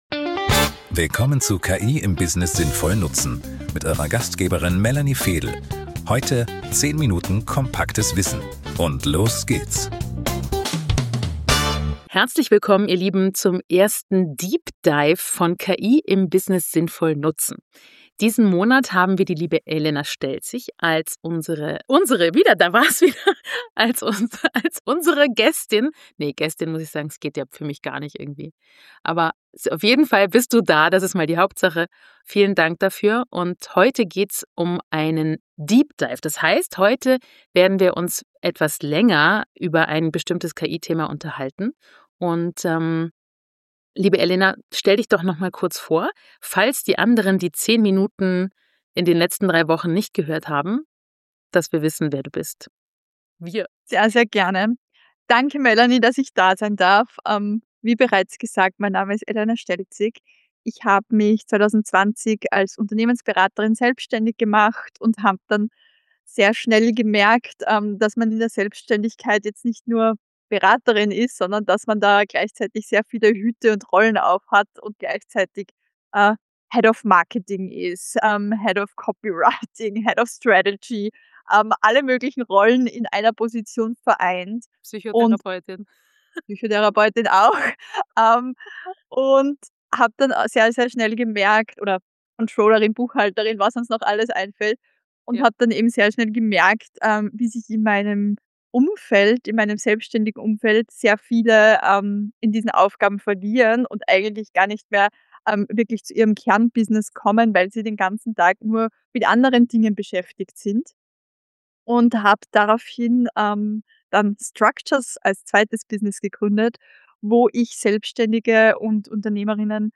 Ein ruhiger, klarer Deep Dive für alle Selbstständigen, EPUs und KMUs, die KI pragmatisch und professionell einsetzen wollen.